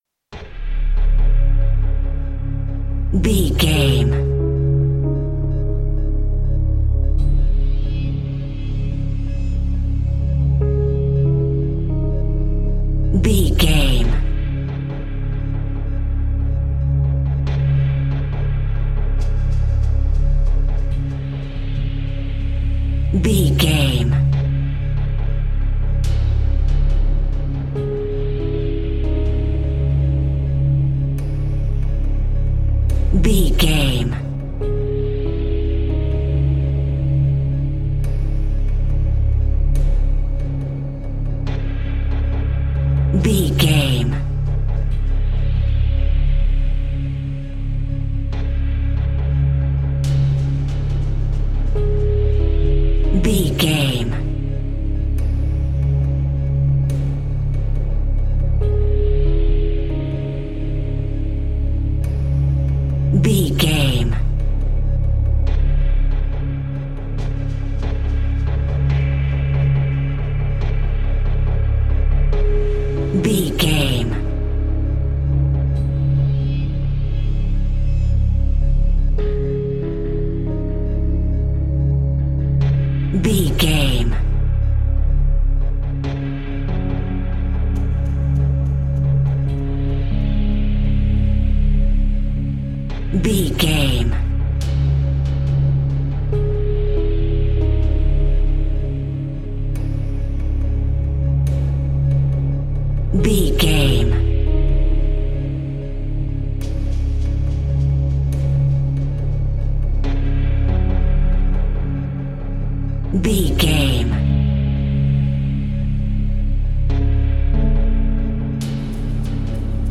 Thriller
Aeolian/Minor
C#
synthesiser
drum machine
percussion